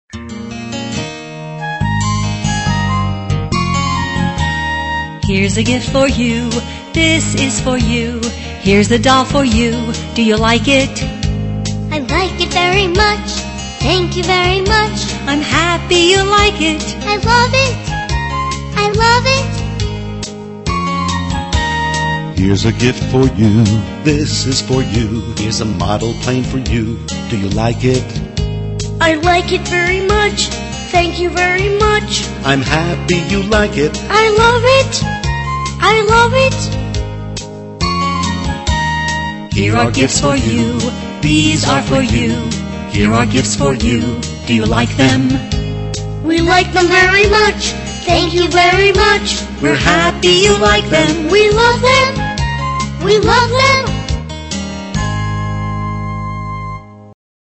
在线英语听力室英语儿歌274首 第62期:Here's a gift for you的听力文件下载,收录了274首发音地道纯正，音乐节奏活泼动人的英文儿歌，从小培养对英语的爱好，为以后萌娃学习更多的英语知识，打下坚实的基础。